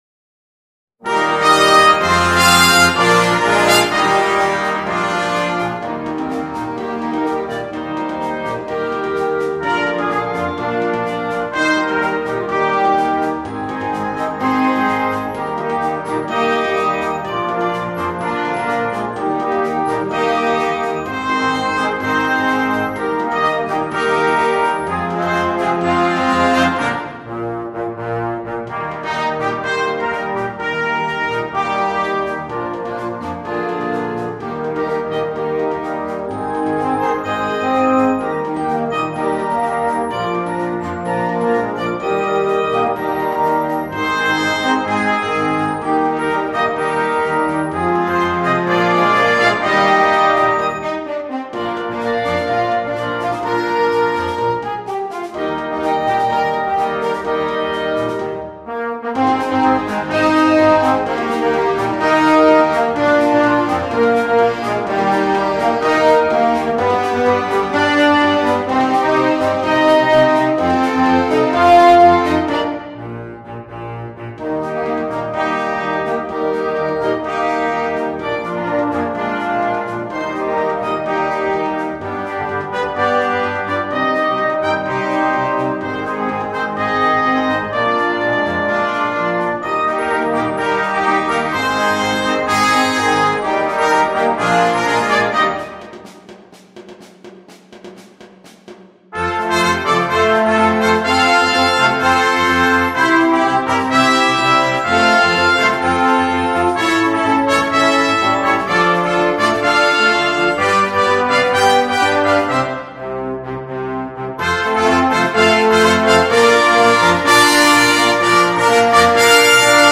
2. Blasorchester
komplette Besetzung
ohne Soloinstrument
Unterhaltung